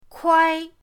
kuai1.mp3